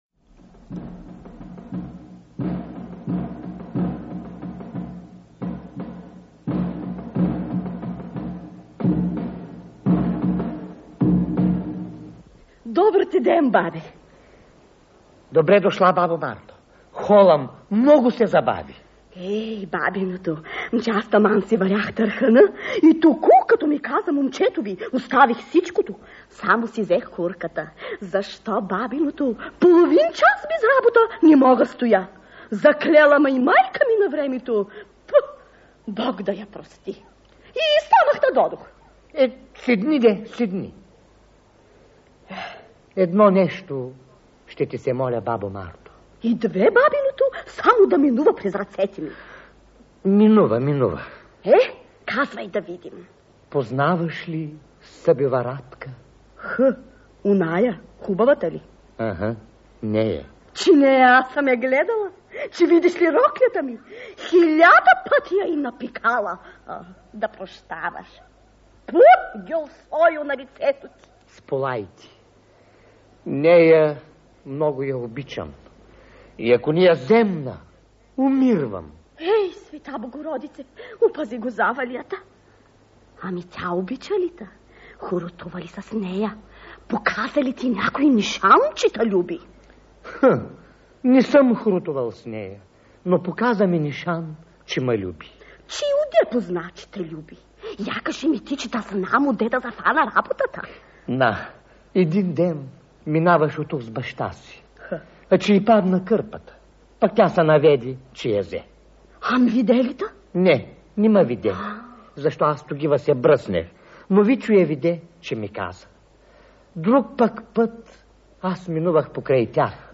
Златни гласове на радиотеатъра